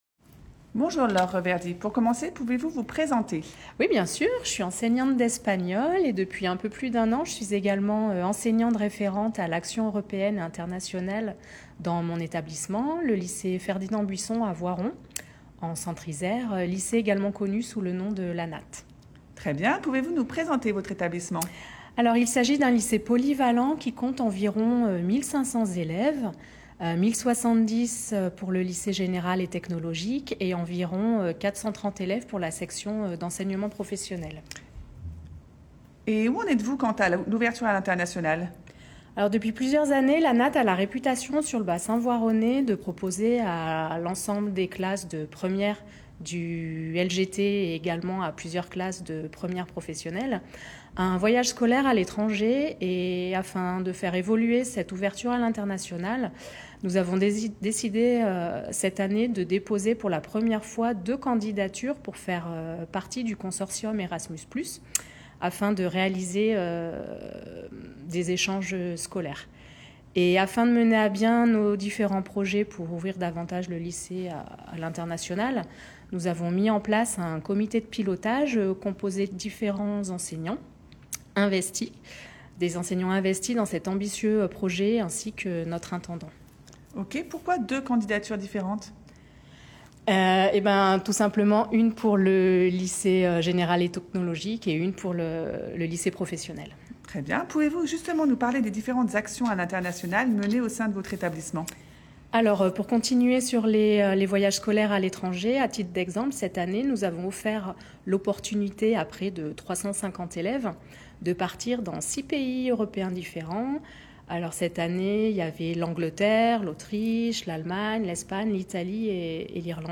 Interview de l'ERAEI